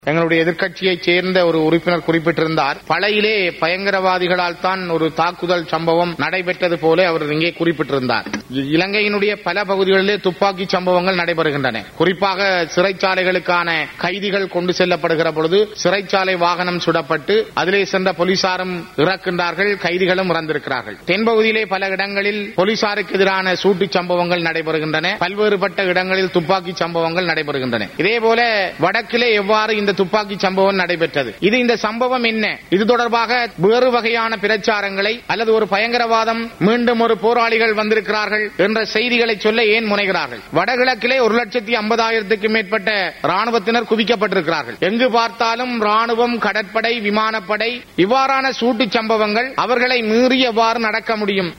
குரல் ஸ்ரீதரன்